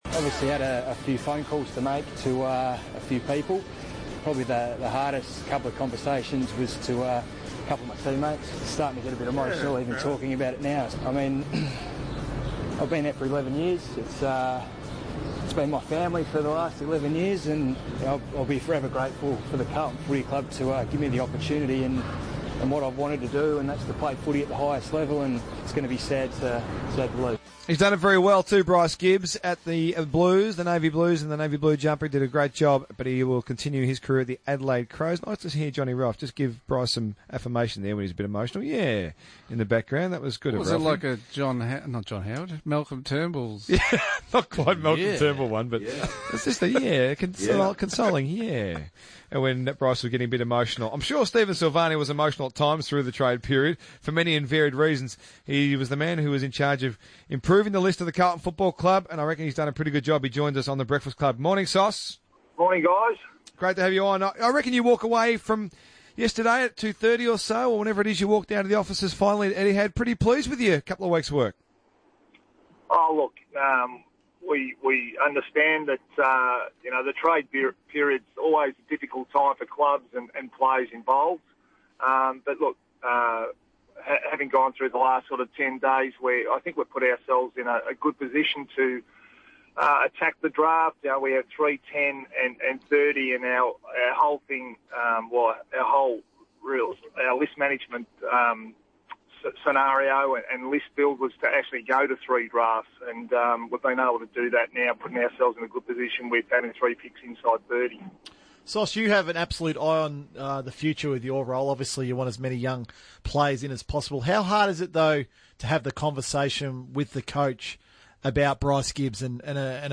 Stephen Silvagni, GM of List Management and Strategy, speaks to RSN Breakfast following the 2017 trade period.